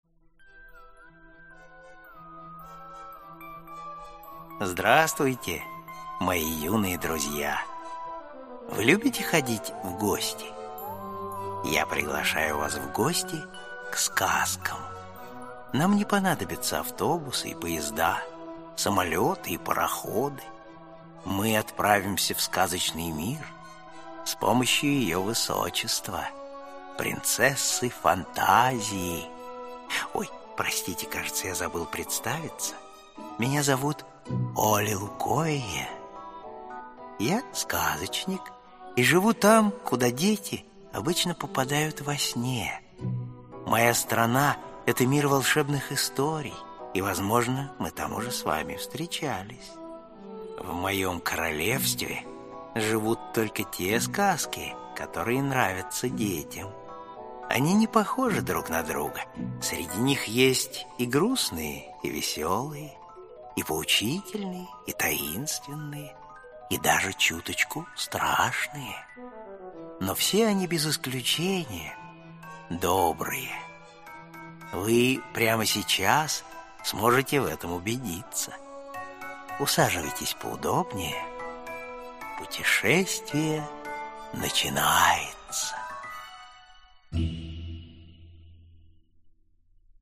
Аудиокнига Сказка о золотом петушке (сборник) | Библиотека аудиокниг
Aудиокнига Сказка о золотом петушке (сборник) Автор Александр Пушкин Читает аудиокнигу Александр Клюквин.